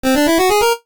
jingles-retro_11.ogg